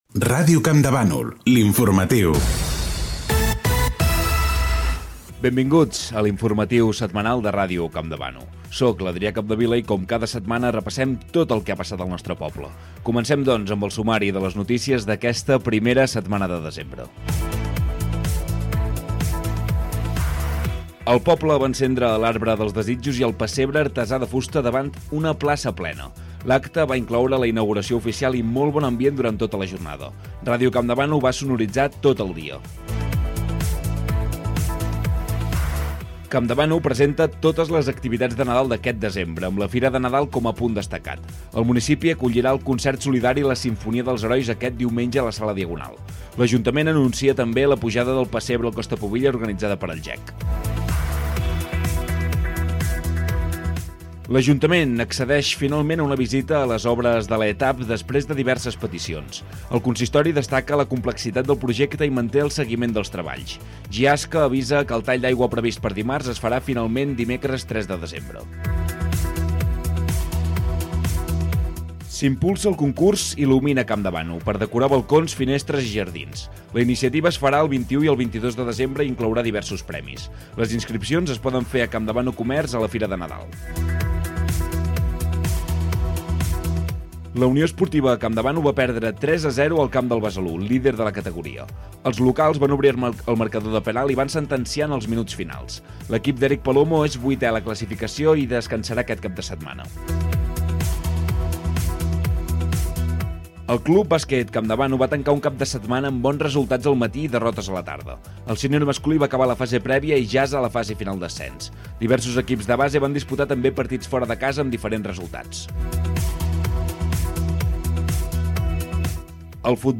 Indicatiu de la ràdio, presentació, sumari informatiu, indicatiu, encesa dels llums de Nadal, estrena del pessebre de fusta de Campdevànol, activitats nadalenques a Campdevànol, obres a les canalitzacions d'aigua, esports.
Gènere radiofònic Informatiu